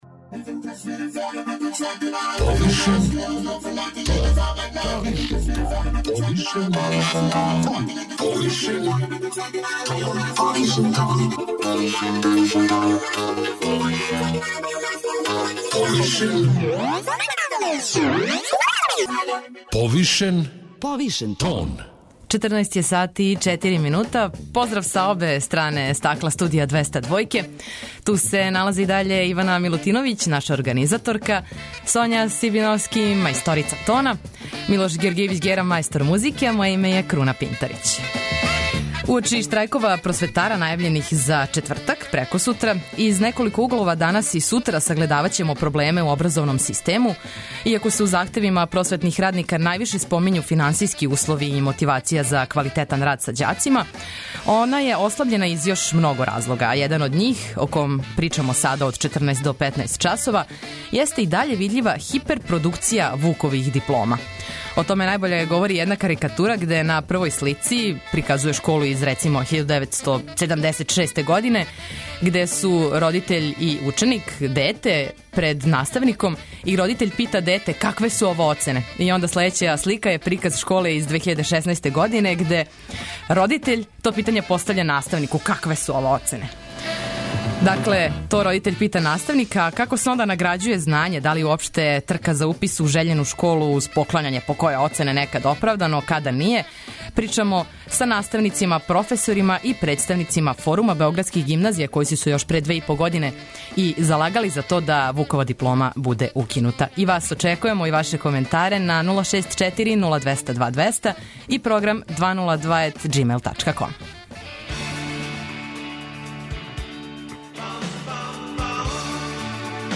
Како се награђује знање, да ли је уопште "трка" за упис у жељену школу уз поклањање покоје оцене некад оправдана, а када није, причамо са наставницима, професорима и представницима Форума београдских гимназија.